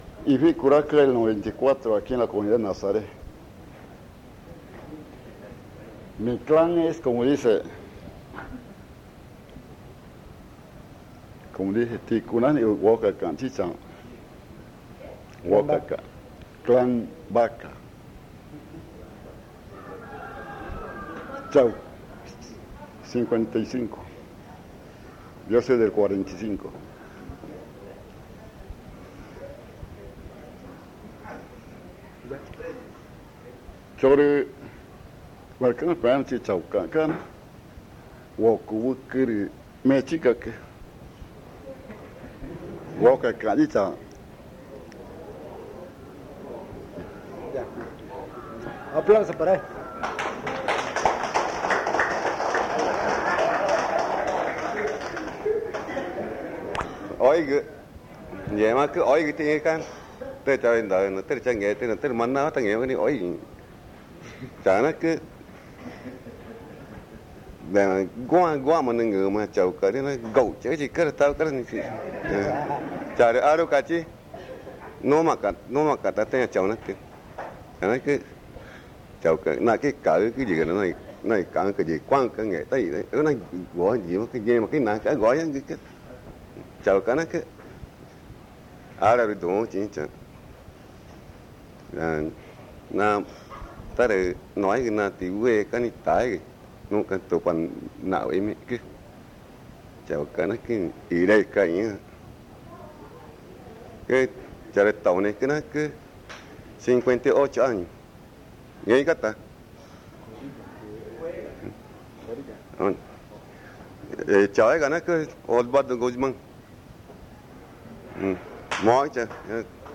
Presentación de los abuelos
San Juan del Socó, río Loretoyacu, Amazonas (Colombia)
Se presentan las abuelas y los abuelos que participaron en los talleres y las grabaciones.
The elders who participated in the workshops and recordings introduce themselves.